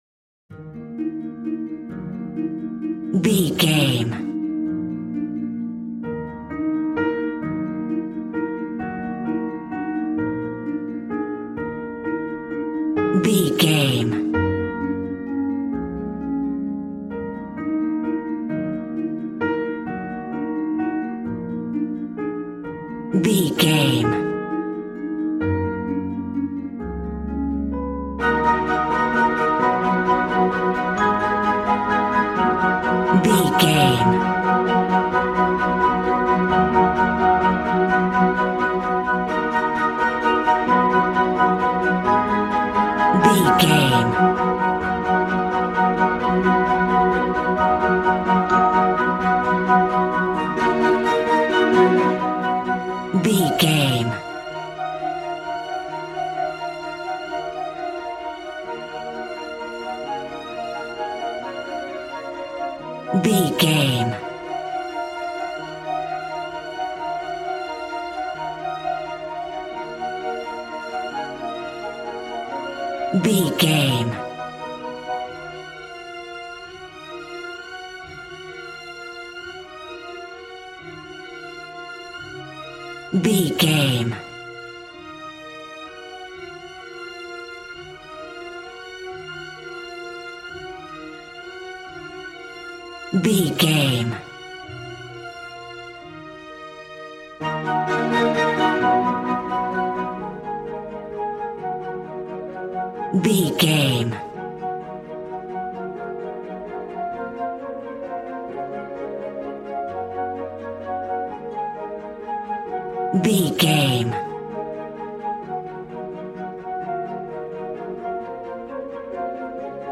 Ionian/Major
G♭
regal
strings
violin